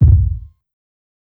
KICK_DOORDIE.wav